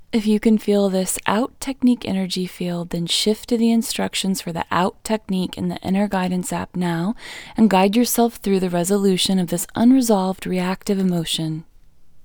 LOCATE OUT English Female 30